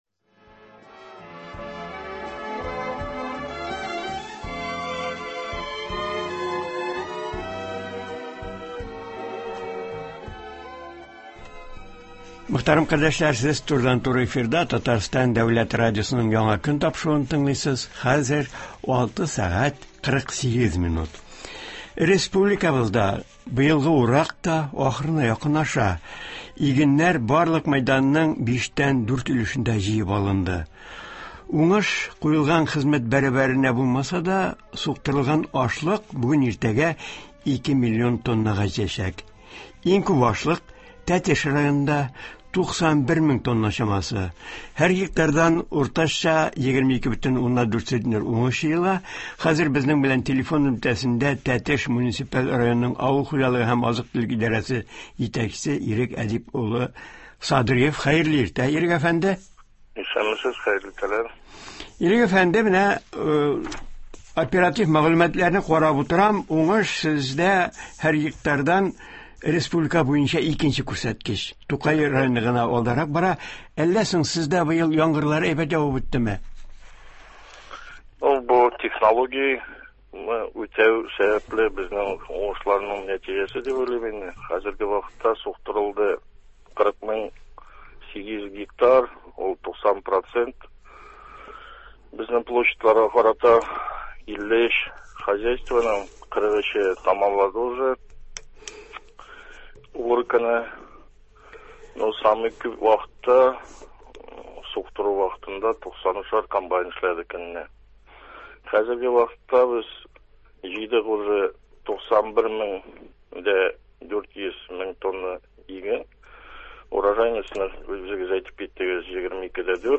Туры эфир (11.08.21)